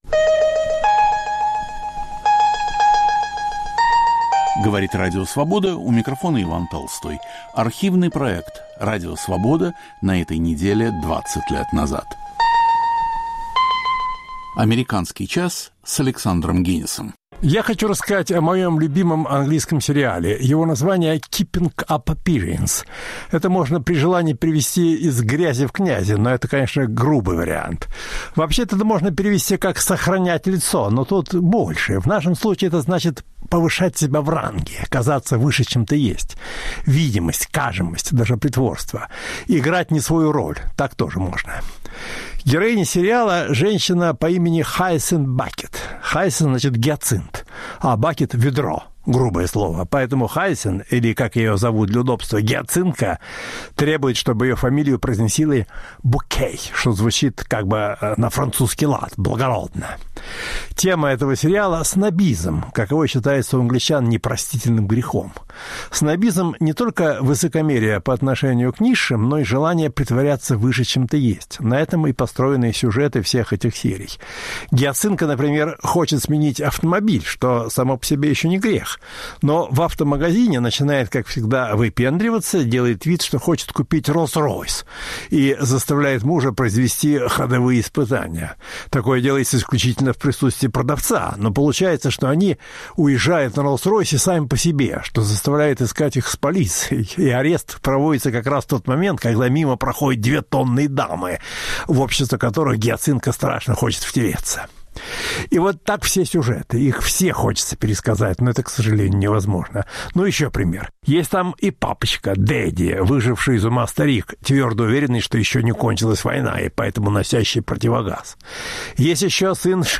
Американские студенты глазами профессоров. Гость "Американского часа" - супермодель Ирина Пантаева. Ведет Александр Генис.